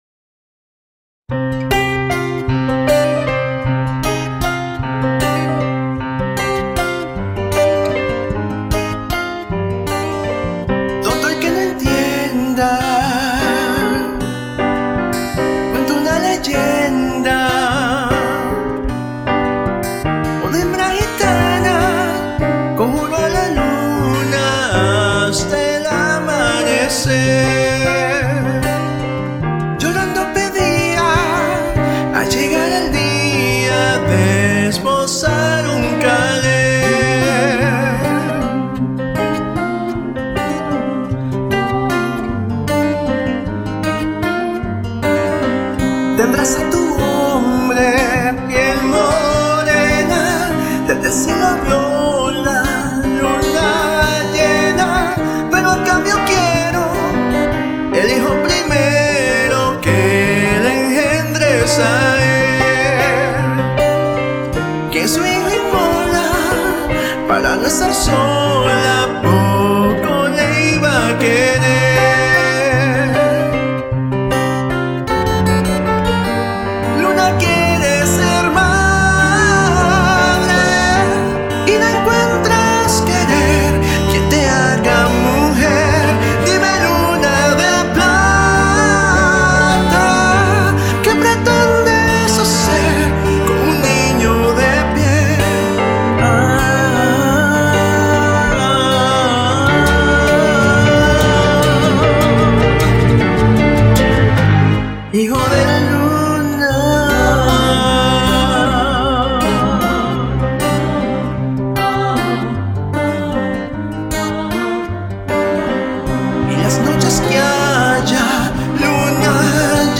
Conver de la cancion